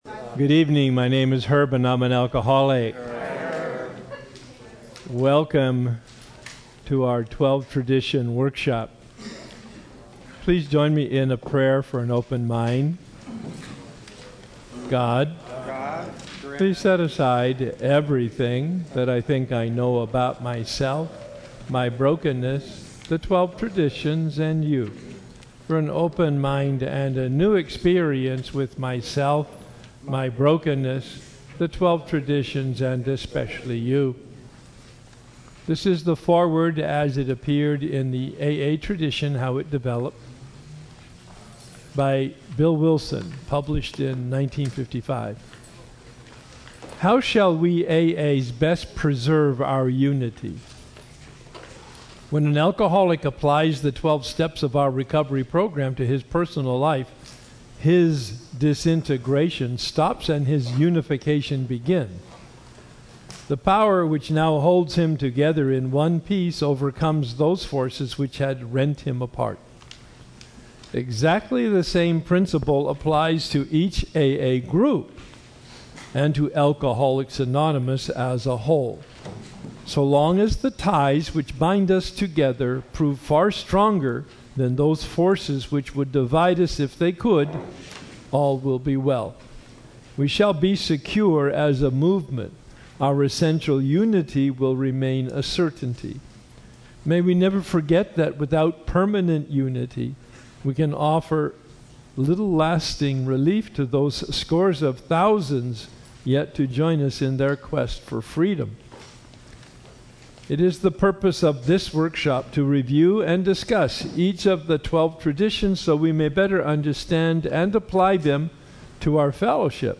Meditation Workshop - Tradition 2